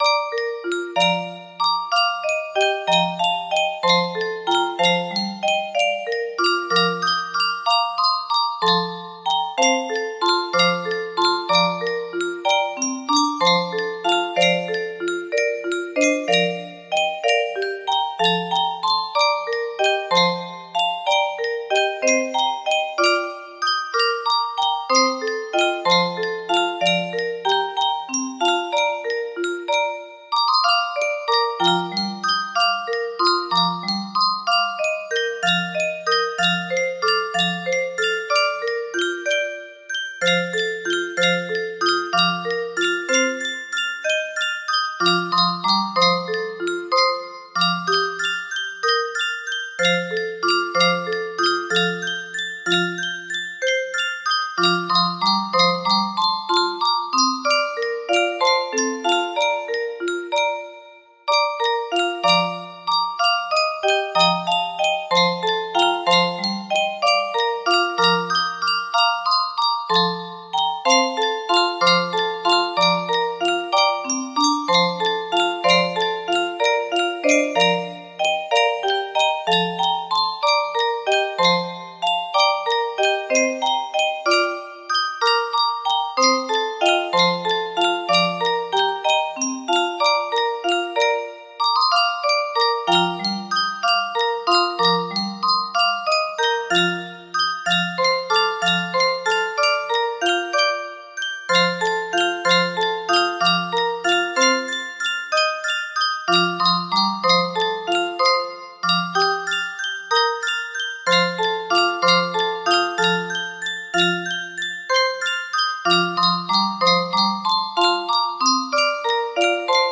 Lady Athenry- Turlough O'Carolan music box melody
Grand Illusions 30 (F scale)